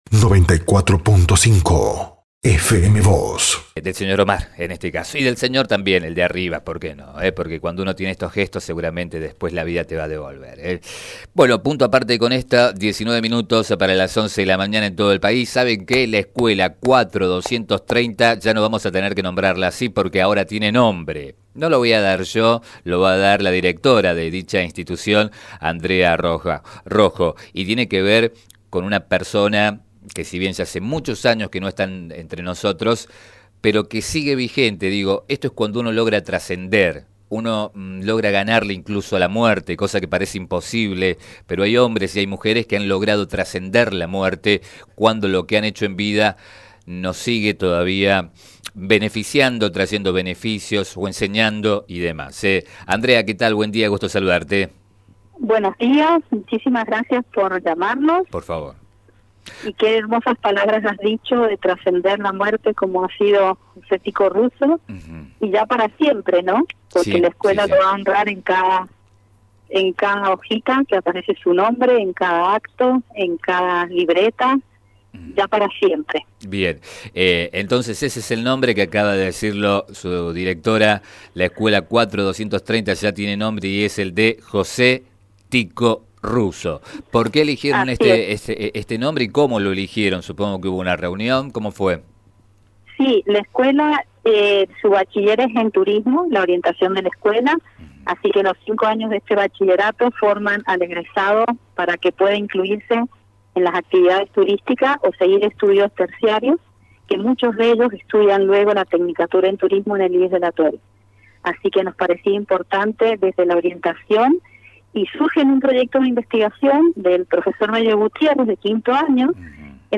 Sobre ello dialogó con FM Vos (94.5) y Diario San Rafael